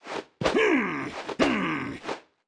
Media:anim_sunburn_barbarian_intro_01.wav 动作音效 anim 查看其技能时触发动作的音效